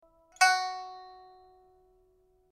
pipa13.mp3